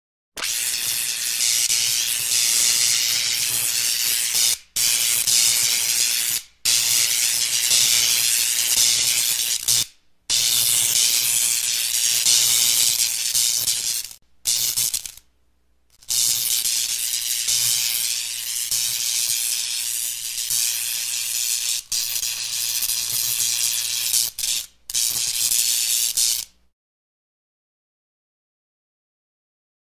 جلوه های صوتی
دانلود صدای اتصالی برق و الکتریسیته 2 از ساعد نیوز با لینک مستقیم و کیفیت بالا